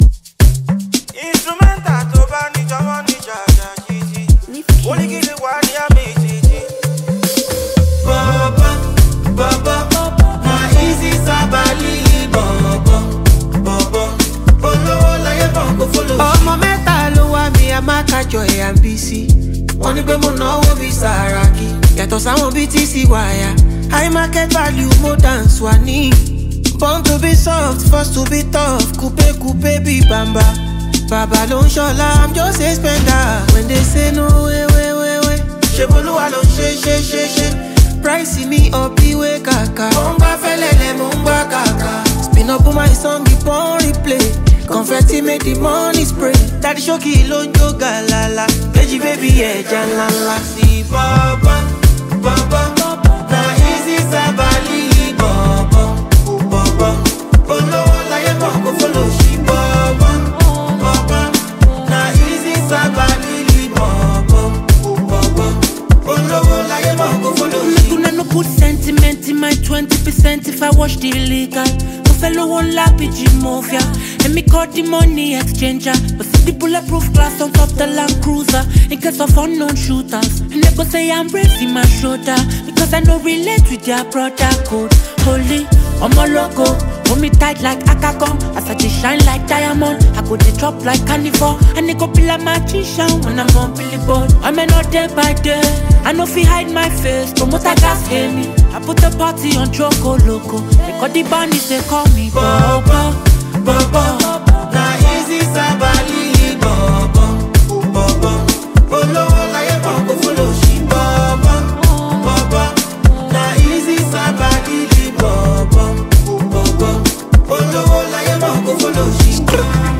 smooth vocals
infectious rhythm, catchy lyrics, and top tier production